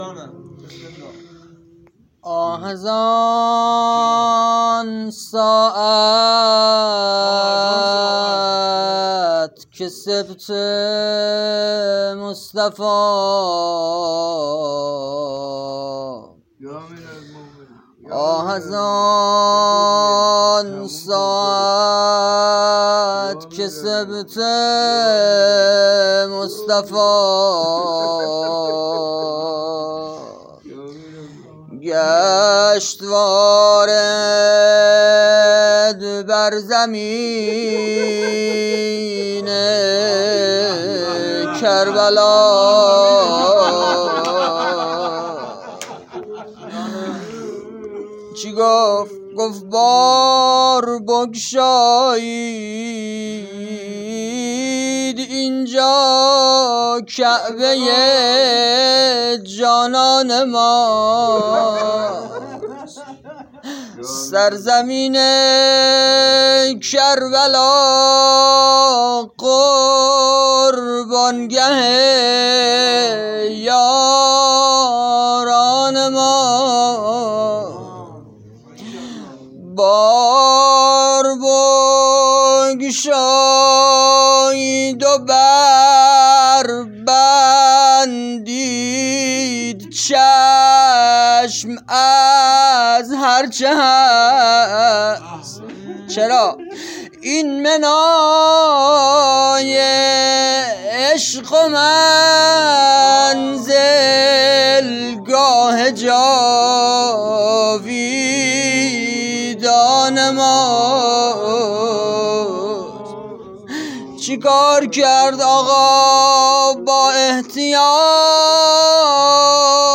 [فایل صوتی] - [روضه] - [شب دوم محرم] - آه از آن ساعت که سبط مصطفی(ص)....
محرم 1403